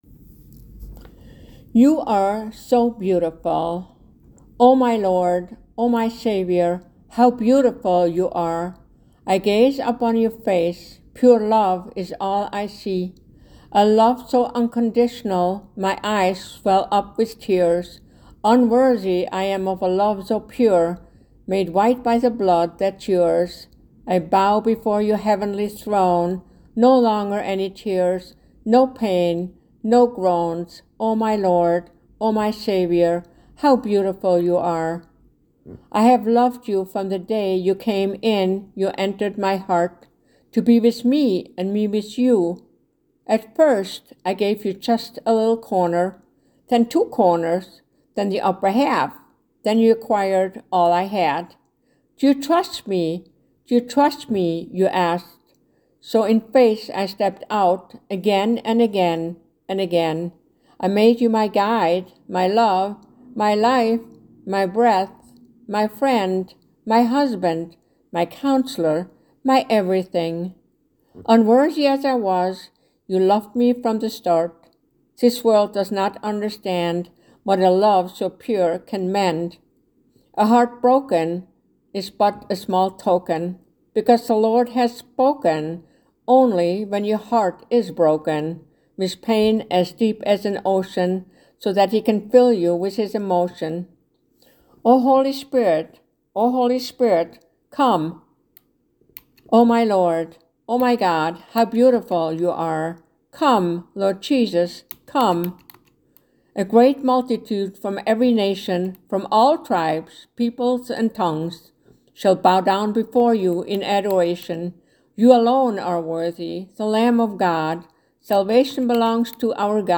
2nd Reading